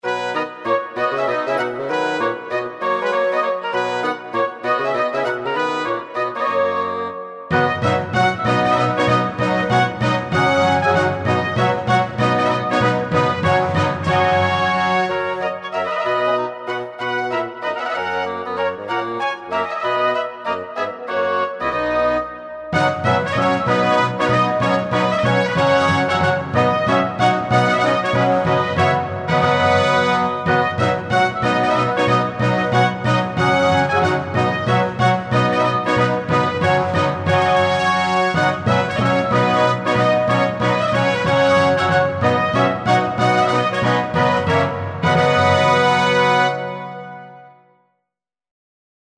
Andante [90-100] amour - clavecin - chasse - roi - cour